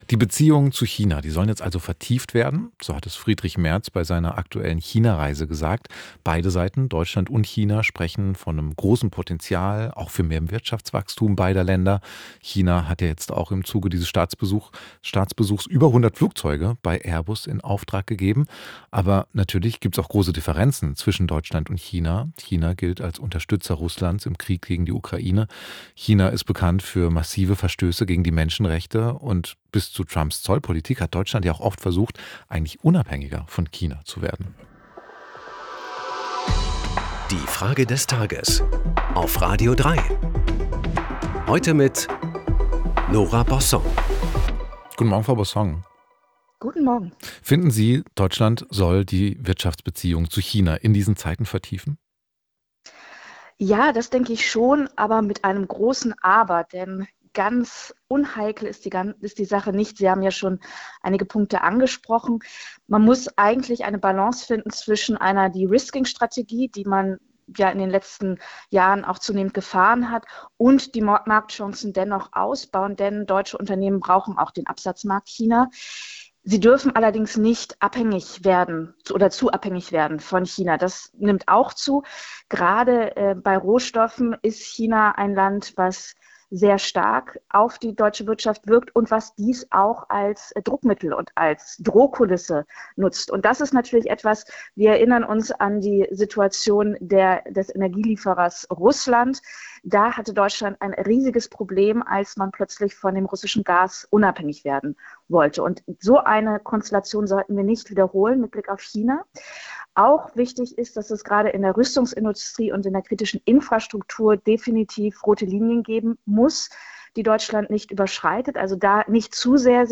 Unsere Frage des Tages an die Schriftstellerin Nora Bossong lautet: